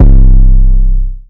808s
[808] (13) so sweet.wav